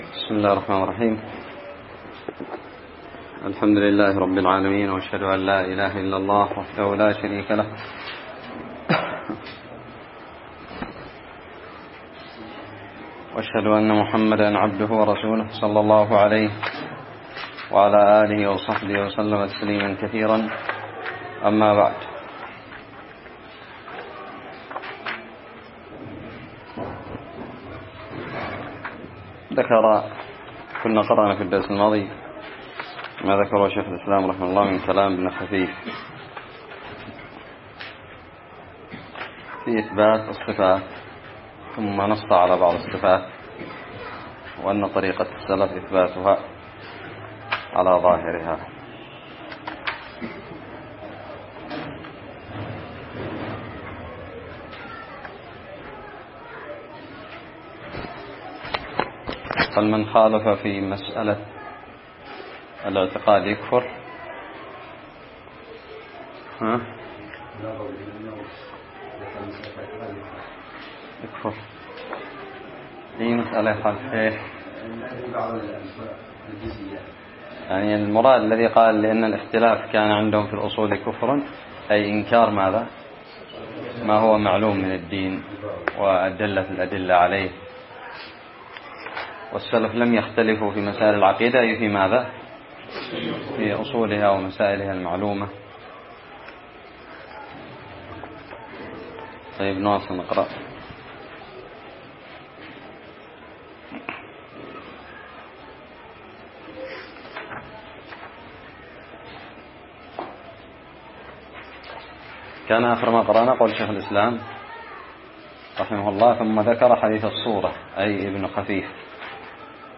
الدرس الخامس عشر من شرح متن الحموية
ألقيت بدار الحديث السلفية للعلوم الشرعية بالضالع